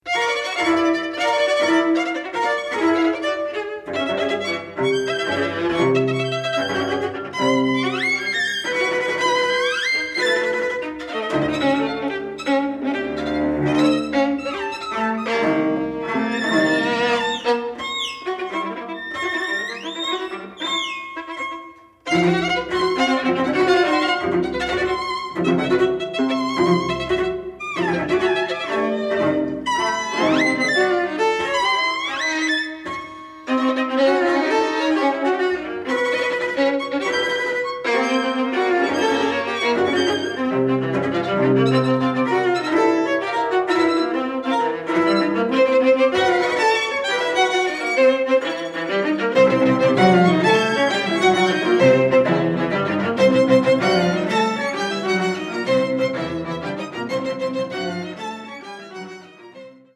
for string quartet
violin
viola
cello